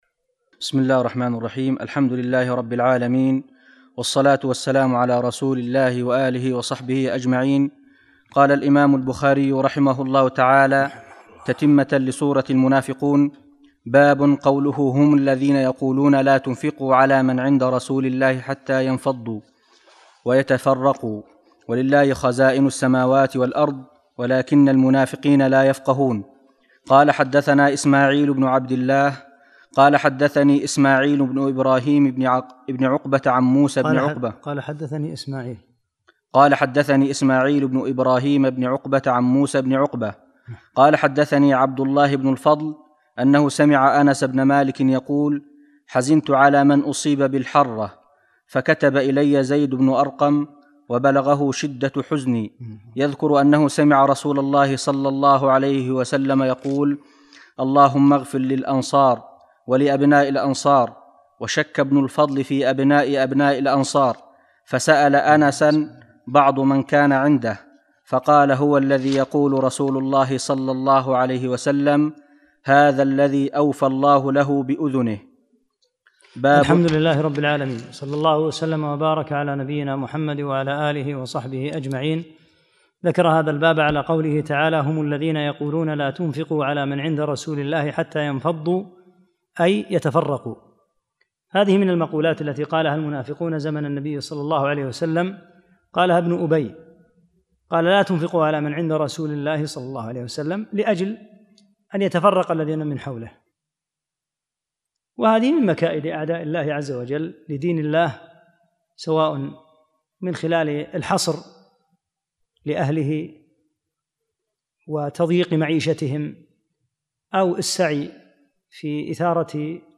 48- الدرس الثامن والأربعون